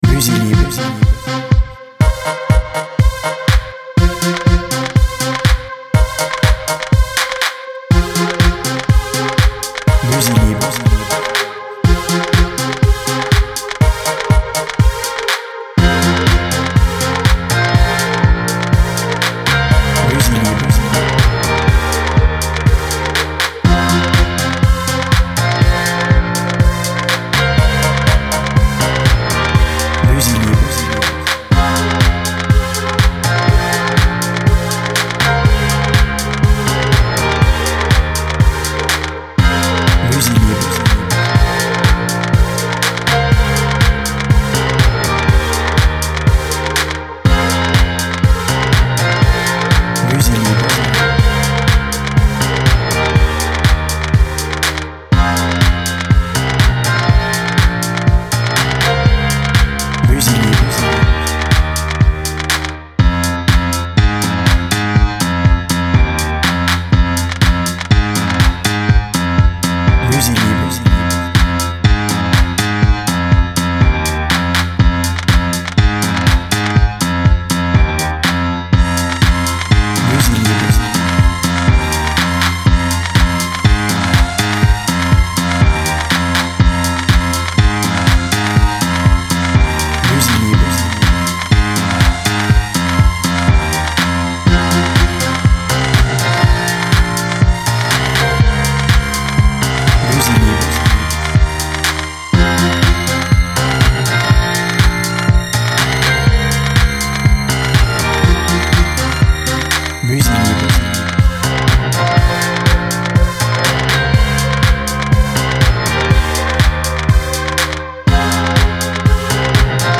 Lyrics less version